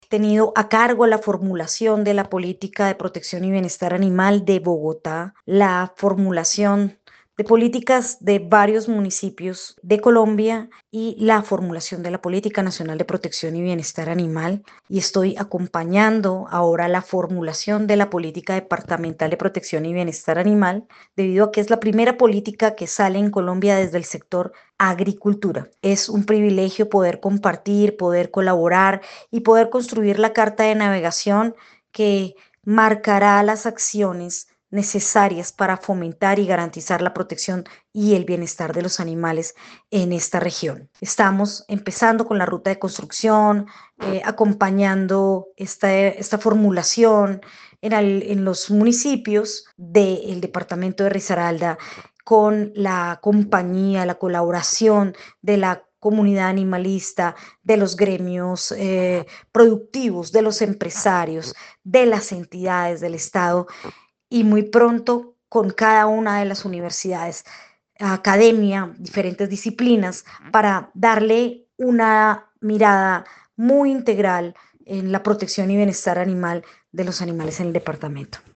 El evento inaugural, realizado en el Concejo Municipal de Dosquebradas, reunió a representantes de la comunidad, expertos en bienestar animal, gremios productivos y entidades del Estado, marcando el inicio de una serie de mesas participativas que se llevarán a cabo en todos los municipios del departamento.